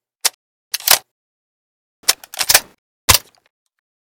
l85_reload.ogg